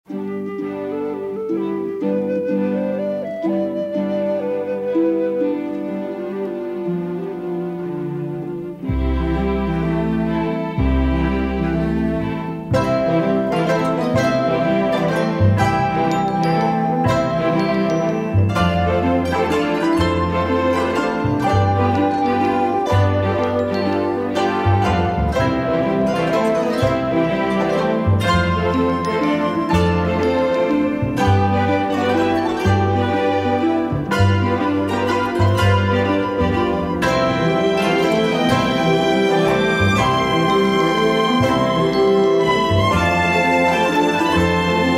a sly, comic theme for saxophones